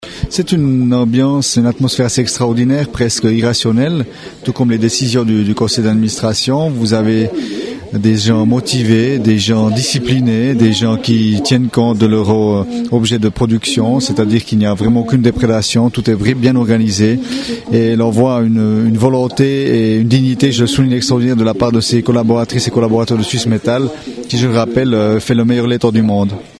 Flavio Torti, maire de Reconvilier